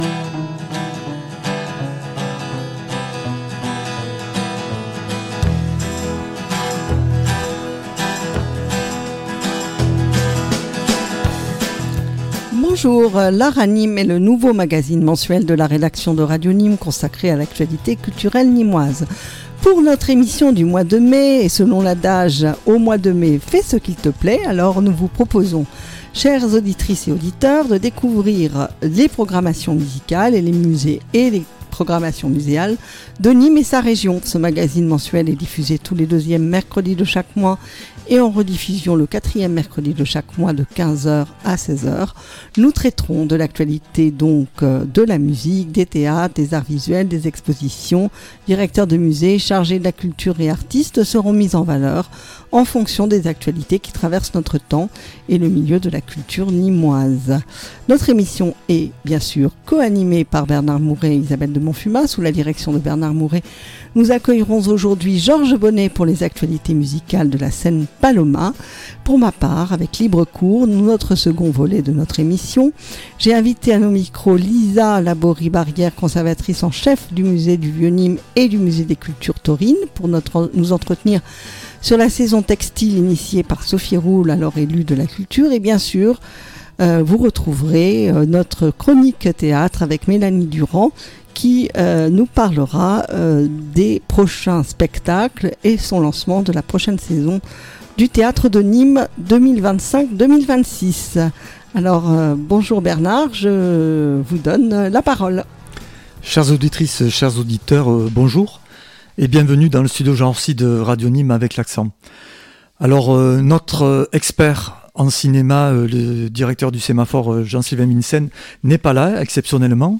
Émission mensuelle « L’Art à Nîmes » - Mercredi 14 Mai 2025 de 15h00 à 16h00. Coup de Projecteur sur les « Expositions Textiles » dans le musées et lieux culturels de la Ville de Nîmes.